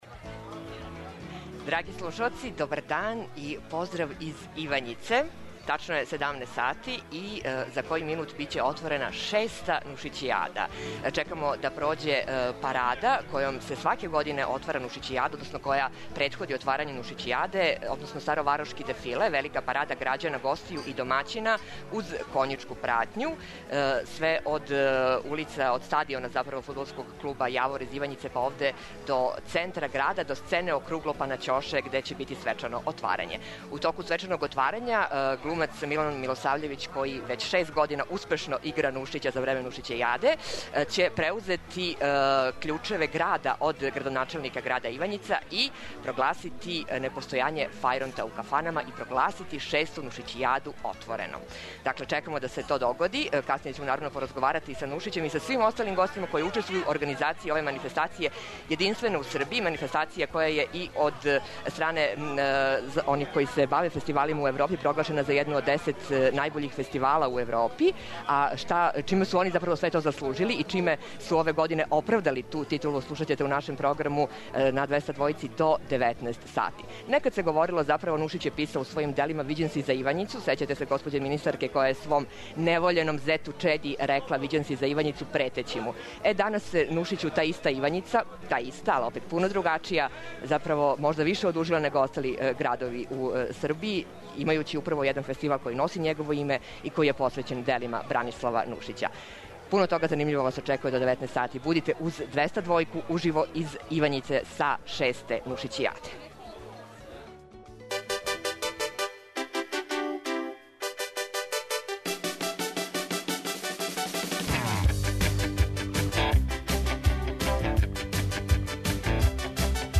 Beograd 202 uživo iz Ivanjice
U petak, od 17č do 19č, 202-jka emituje uživo emisiju iz Ivanjice, sa otvaranja “Nušićijade”, koja će biti održana pod sloganom “Smeh kao lek”. O sadržaju ovogodišnje manifestacije, posvećene jednom od naših najvećih komediografa Branislavu Nušiću, razgovaraćemo sa gostima i organizatorima festivala, koji je proglašen najboljom tradicionalnom manifestacijom u našoj zemlji.